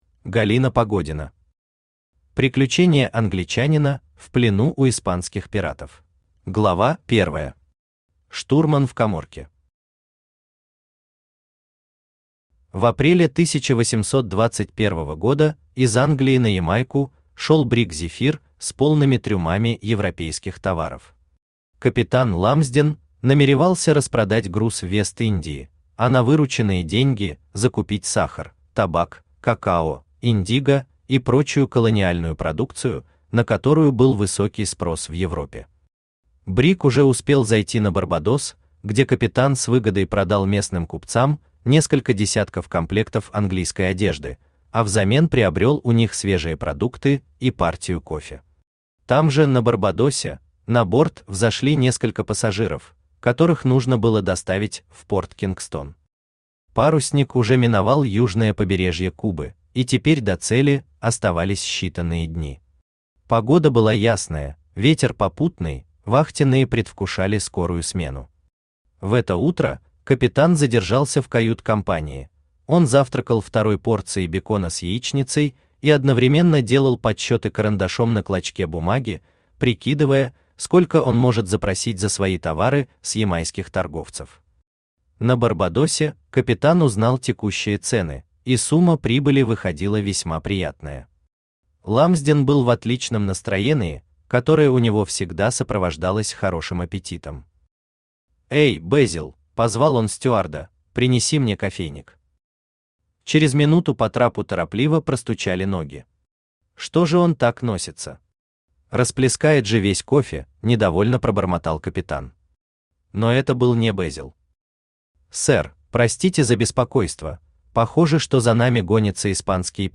Аудиокнига Приключения англичанина в плену у испанских пиратов | Библиотека аудиокниг
Читает аудиокнигу Авточтец ЛитРес.